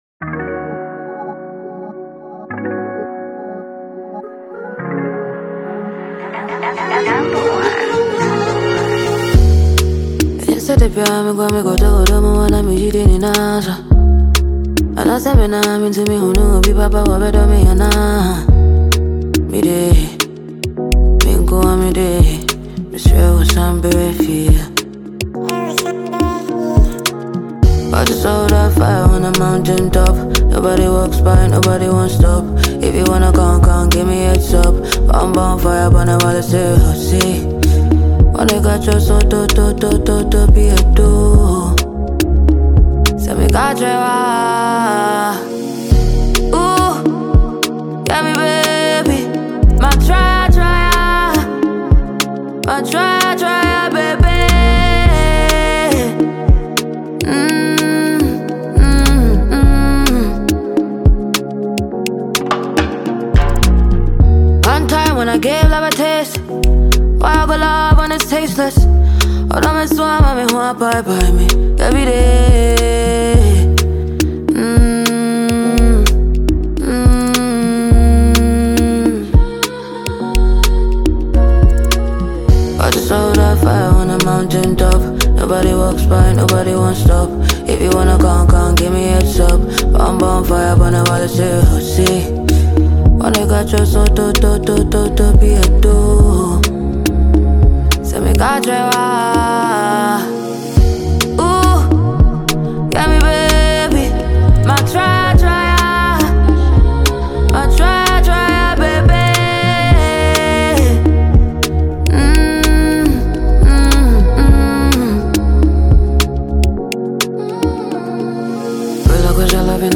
Afro-soul